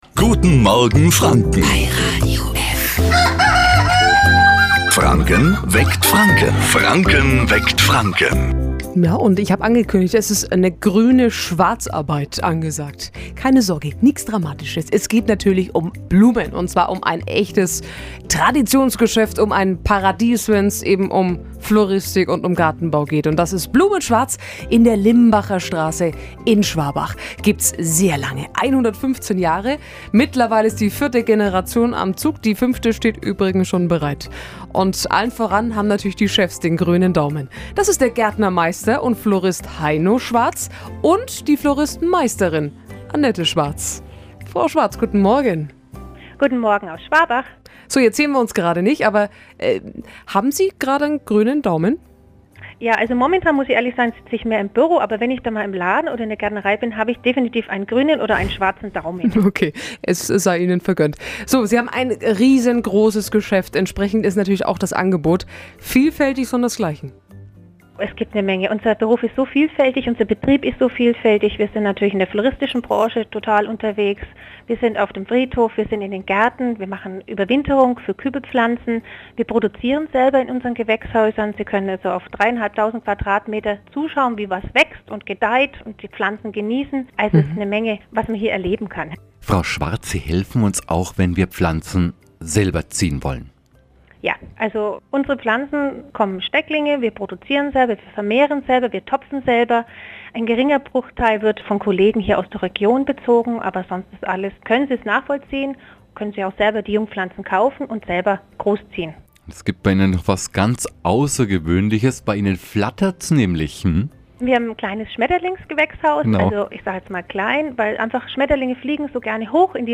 Radiointerview